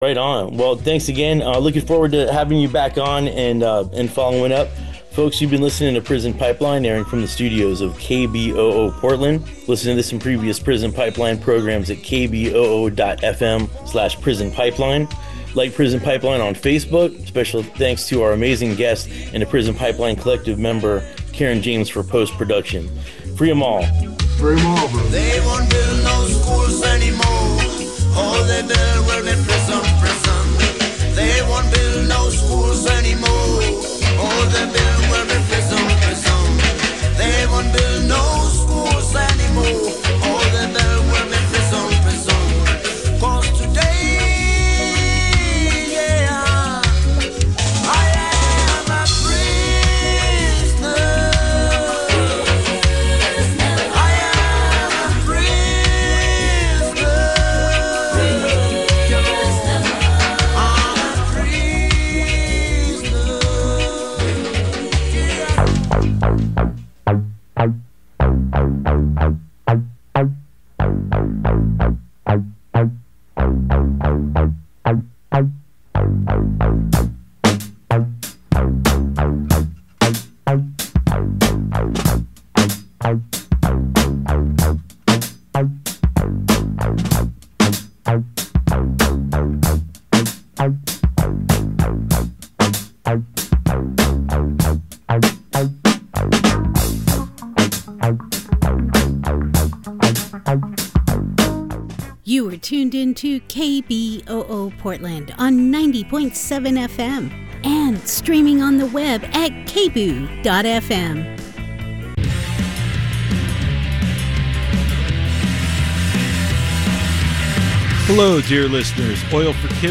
Daily Hip Hop Talk Show